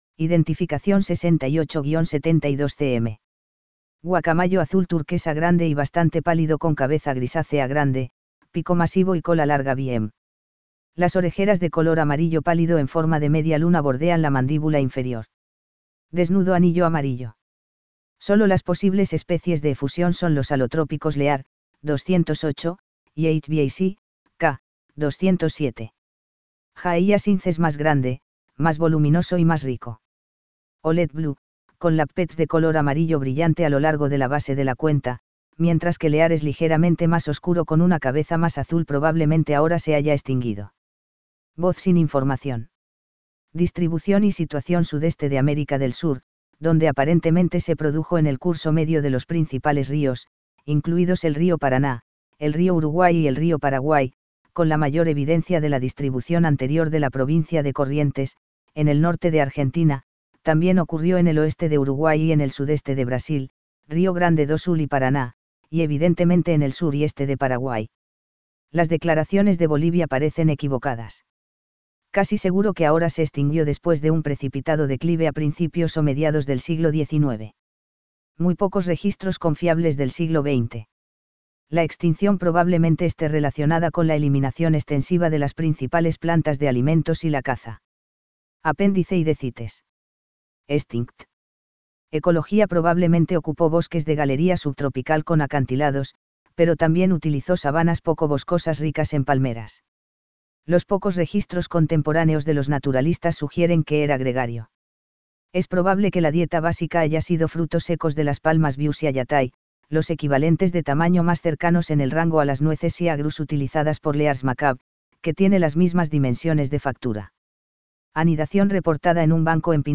Anodorhynchus glaucus - Guacamayo azul
guacamayoazul.mp3